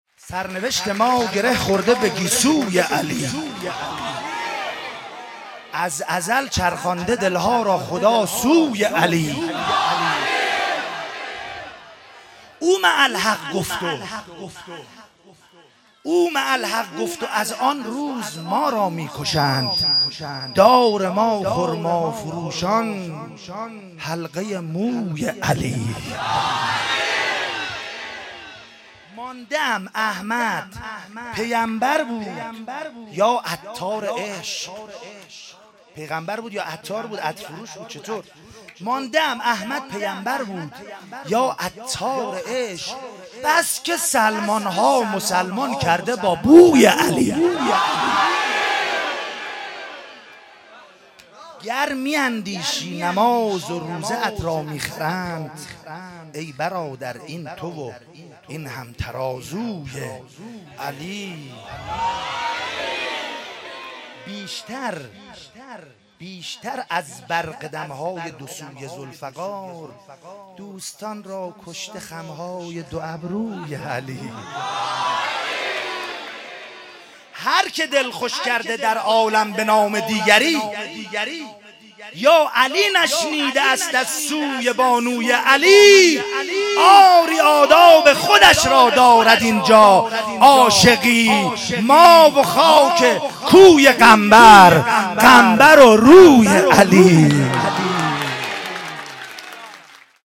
جشن کوثر ولایت15-مجمع دلسوختگان بقیع- مدح